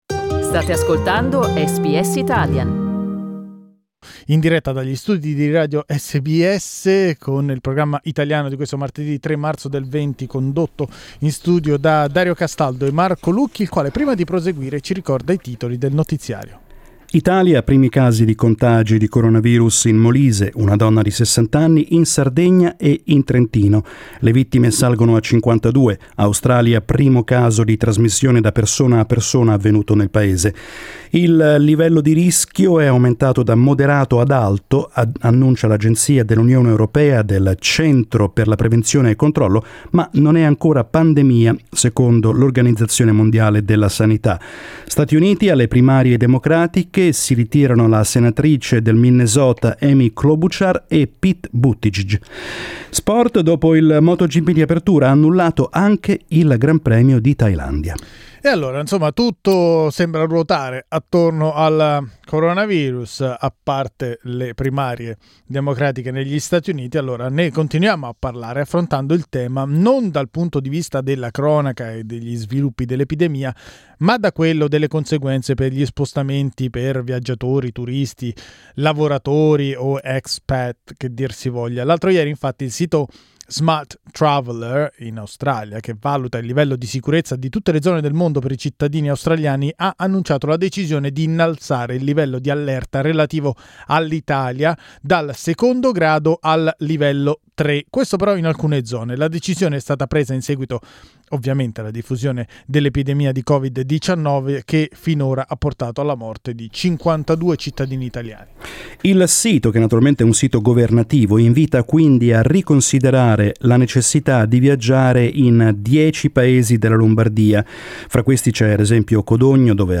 We asked our audience, and interviewed Italian Ambassador Francesca Tardioli about the current emergency.
Speaking from Canberra, Italian Ambassador Francesca Tardioli explained how Italian authorities are closely monitoring the situation.